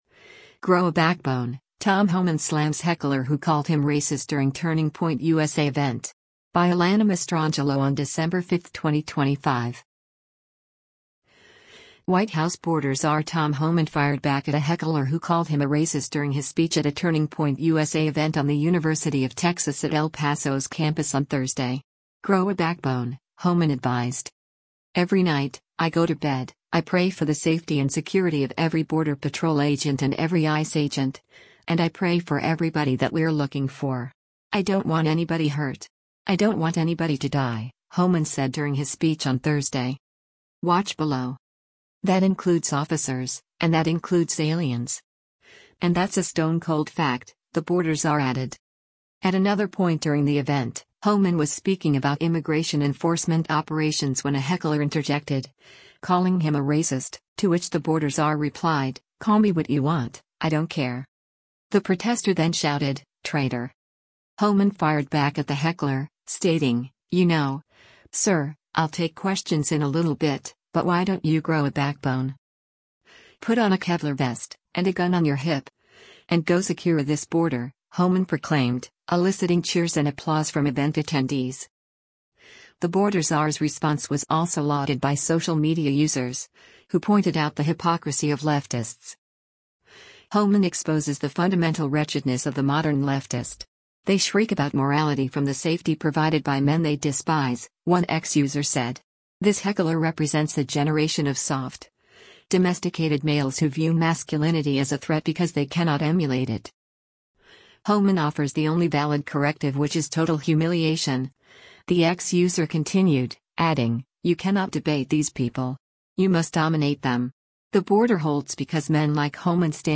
White House border czar Tom Homan fired back at a heckler who called him a “racist” during his speech at a Turning Point USA event on the University of Texas at El Paso’s campus on Thursday.
“Put on a Kevlar vest, and a gun on your hip, and go secure this border,” Homan proclaimed, eliciting cheers and applause from event attendees.